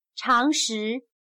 常识/Chángshì/Conocimiento general o elemental, sentido común.